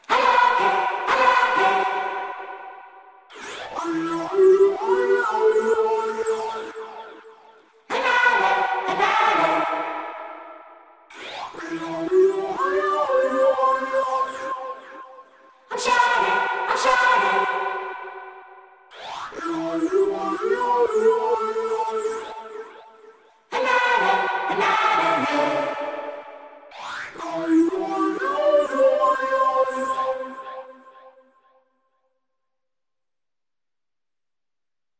Accapella